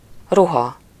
Ääntäminen
Ääntäminen France: IPA: [kɔs.tym] Haettu sana löytyi näillä lähdekielillä: ranska Käännös Ääninäyte 1. ruha 2. öltözet 3. öltöny 4. viselet 5. jelmez 6. kosztüm Suku: m .